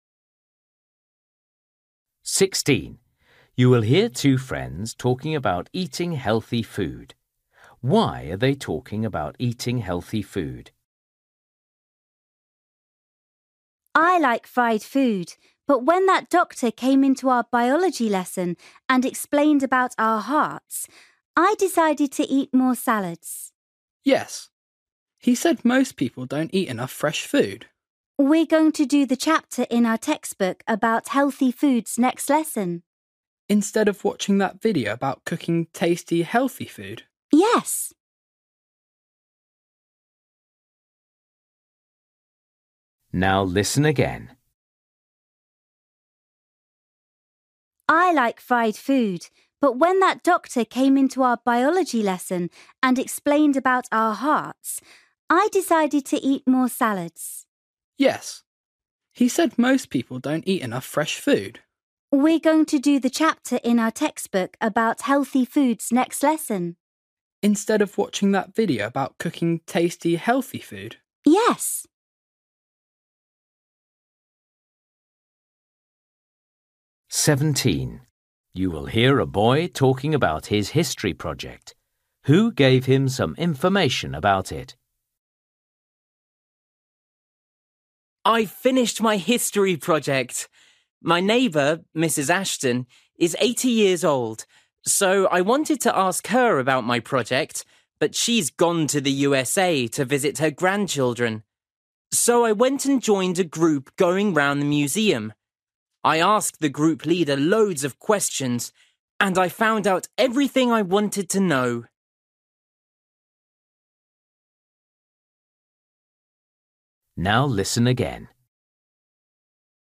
Listening: everyday short conversations
16   You will hear two friends talking about eating healthy food. Why are they talking about eating healthy food?
17   You will hear a boy talking about his history project. Who gave him some information about it?
19   You will hear a teacher talking about a problem. Where is there a problem?